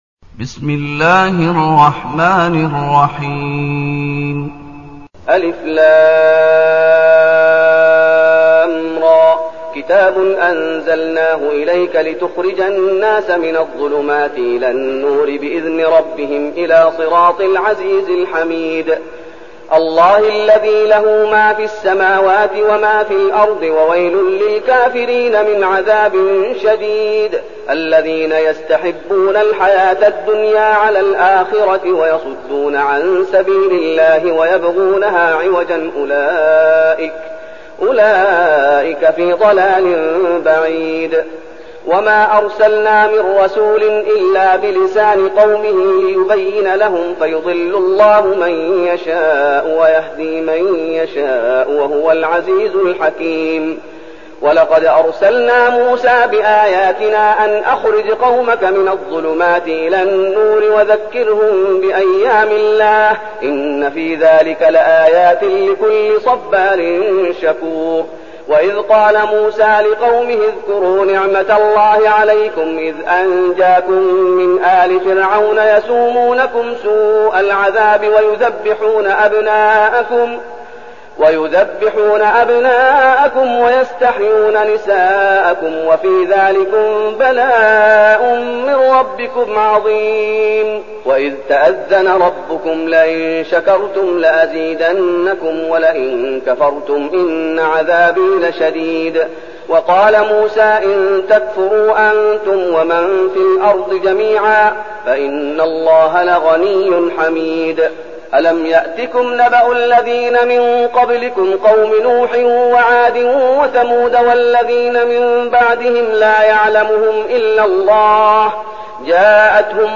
المكان: المسجد النبوي الشيخ: فضيلة الشيخ محمد أيوب فضيلة الشيخ محمد أيوب إبراهيم